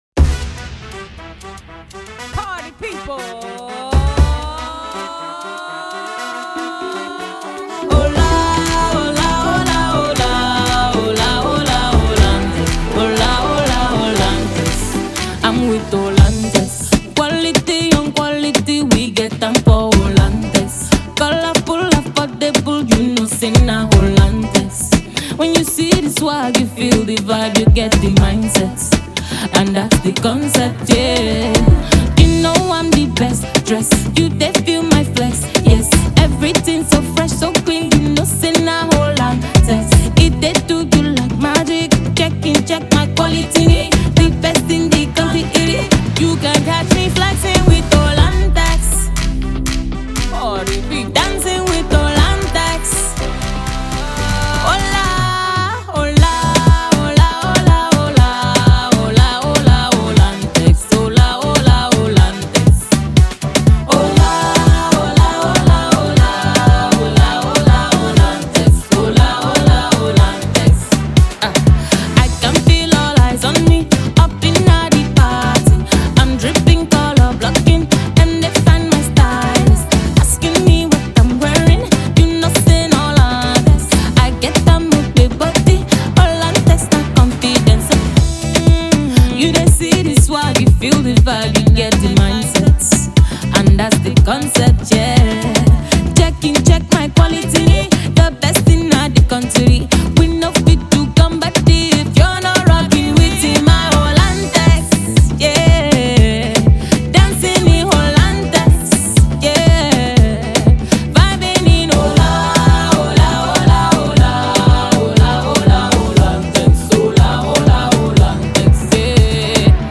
Nigerian Afropop singer
African Music You may also like